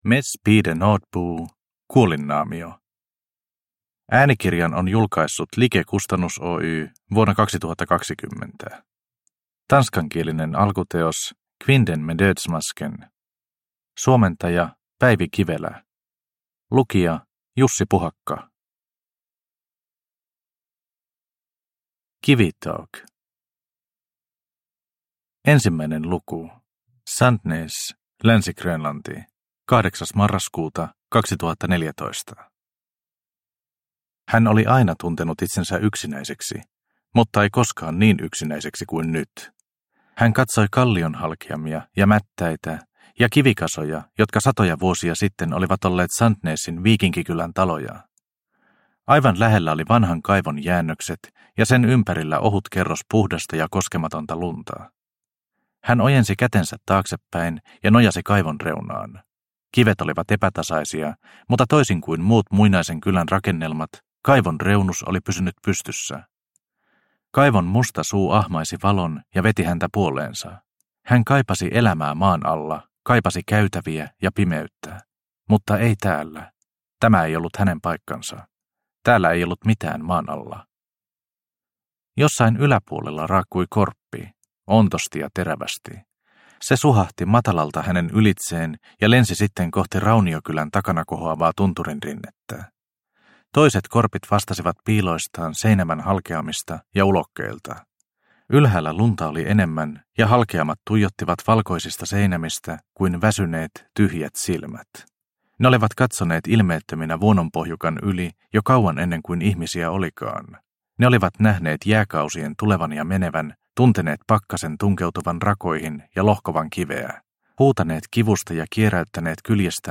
Kuolinnaamio – Ljudbok – Laddas ner